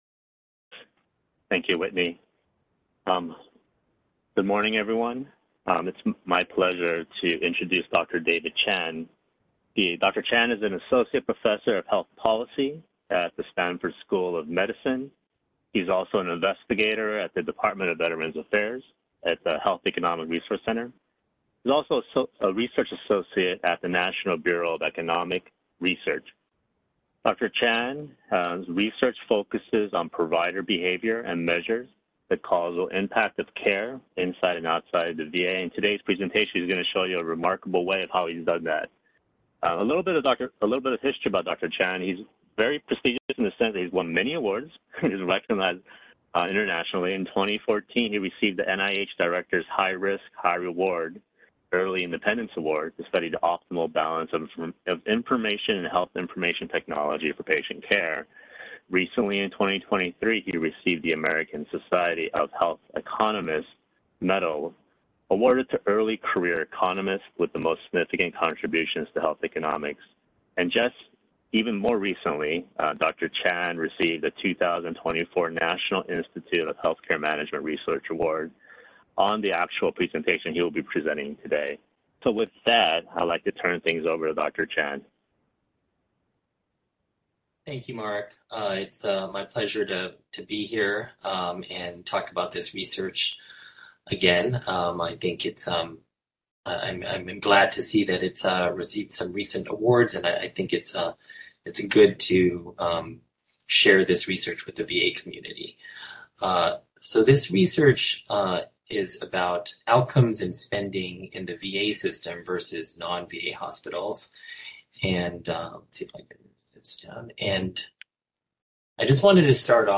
HERC Health Economics Seminar